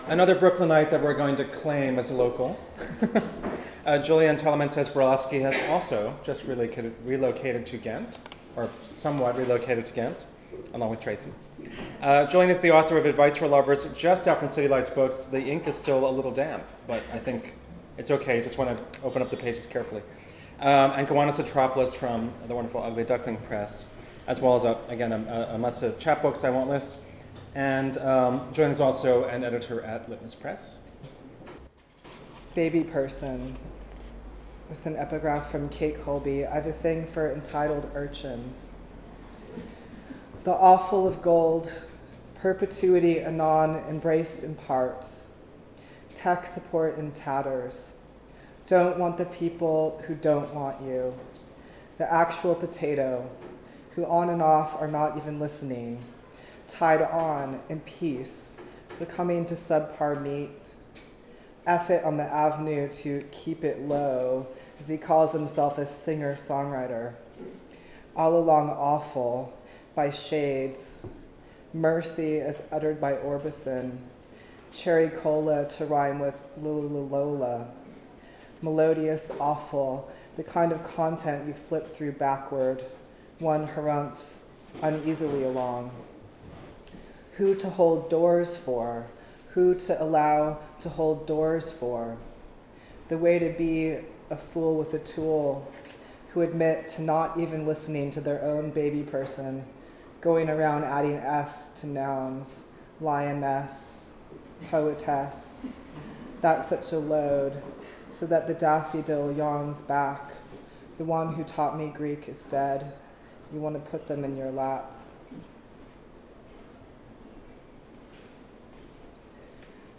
7th Annual Hudson Valley Literary Festival: May 19, 2012: 11am- 4pm
Hudson Hall at the Historic Hudson Opera House